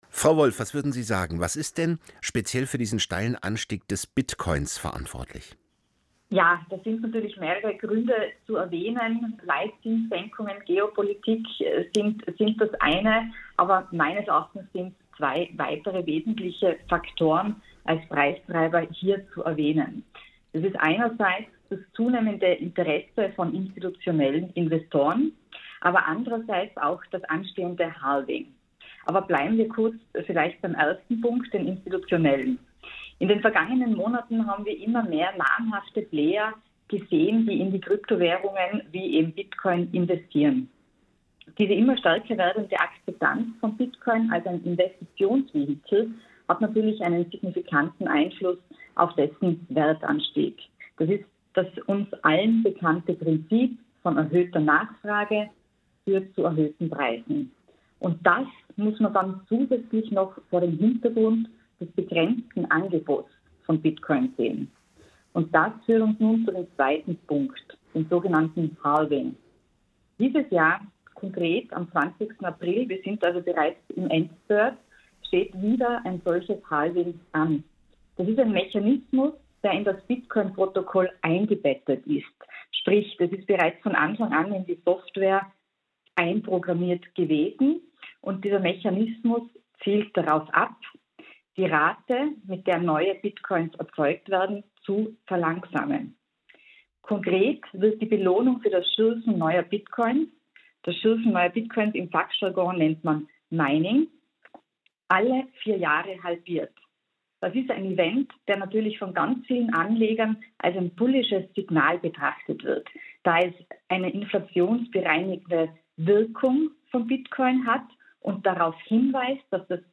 Quelle: SWR Aktuell Radio